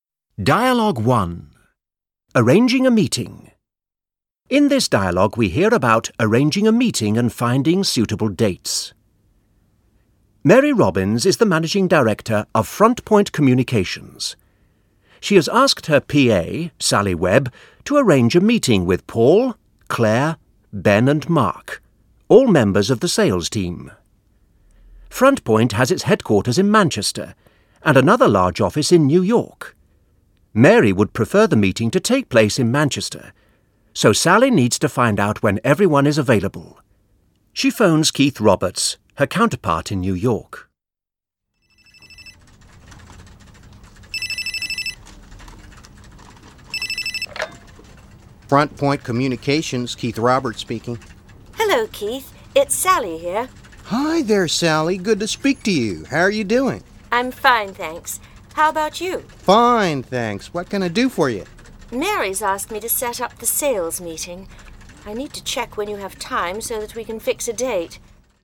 Audio/Hörbuch
• Zehn hörspielartige Dialogszenen mit den wichtigsten Gesprächssituationen in Meetings auf Audio-CD und als MP3-Download.
• Britisches und amerikanisches Englisch.